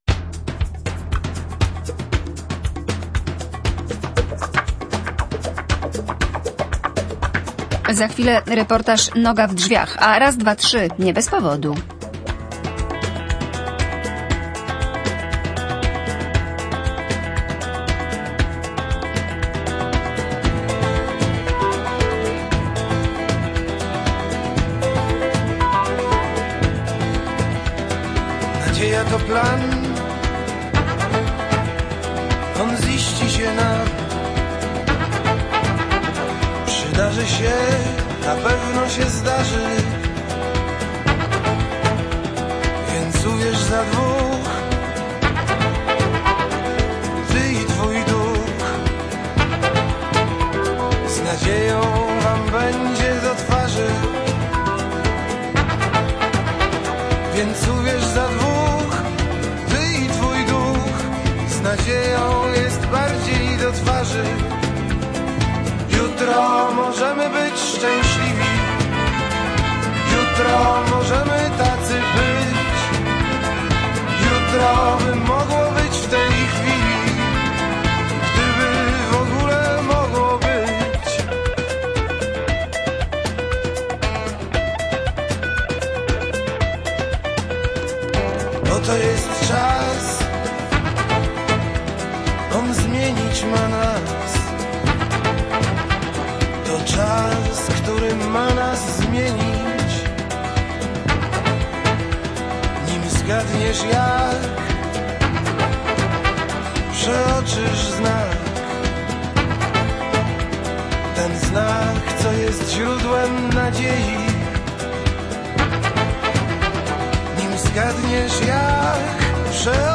Noga w drzwiach - reportaż